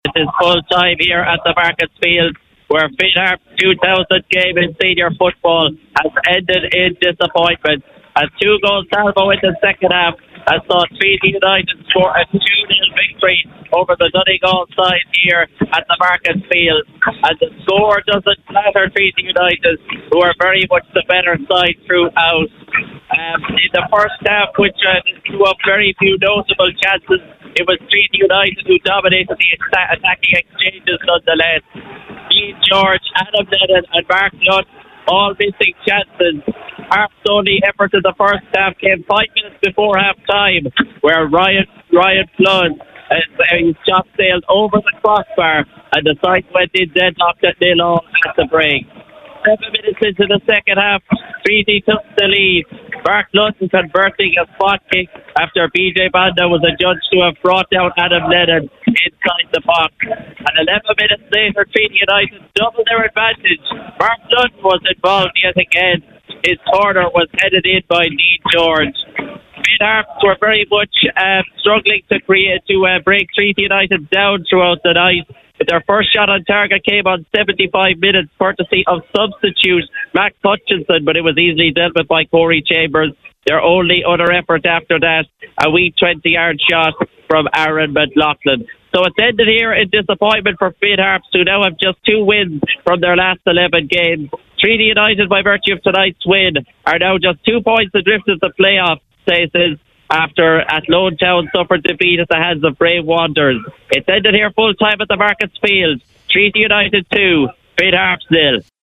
FT Report: Treaty United 2 Finn Harps 0